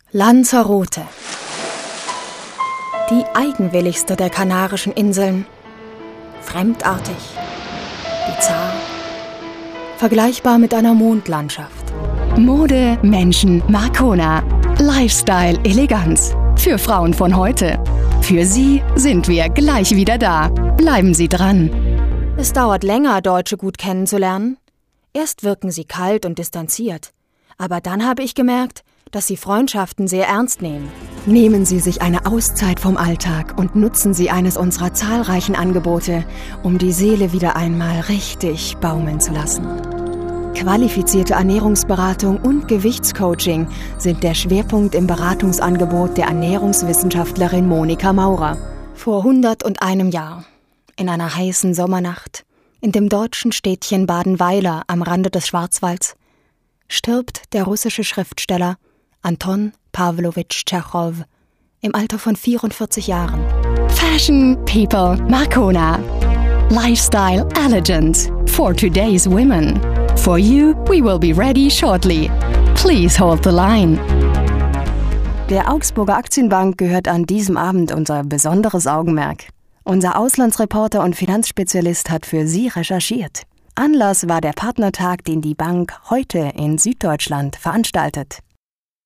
Junge bis mittelalte vielseitig einsetzbare Stimme für alle Arten von Audioproduktionen.
deutsche Sprecherin Junge bis mittelalte vielseitig einsetzbare Stimme,
Sprechprobe: Industrie (Muttersprache):
german female voice over artist